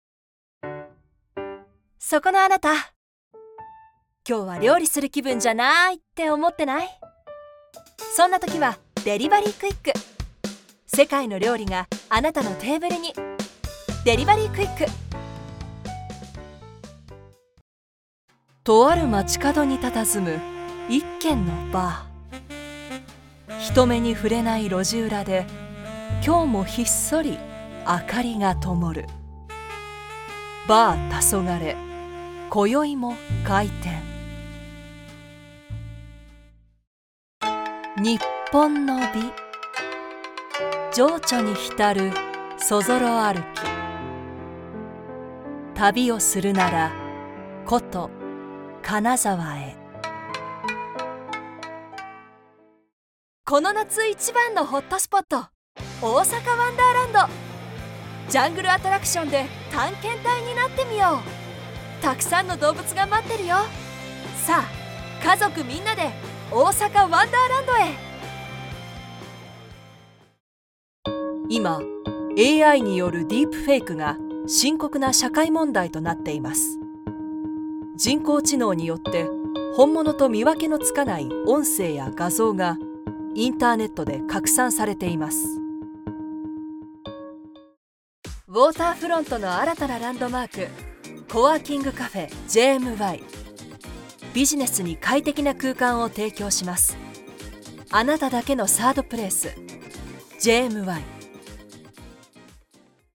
Commercial, Deep, Reliable, Friendly, Corporate
Corporate
Her voice is versatile, stylish, luxurious, authentic, yet believable